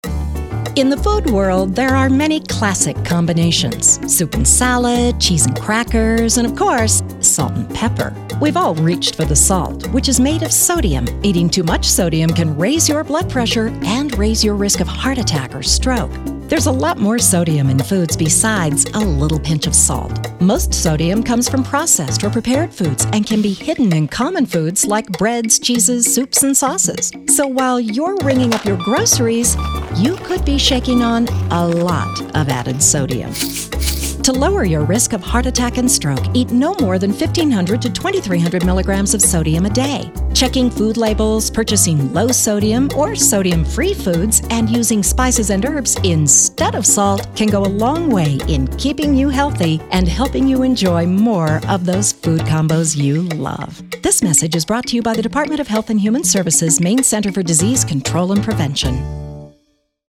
Sodium Radio Spot